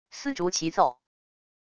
丝竹齐奏wav音频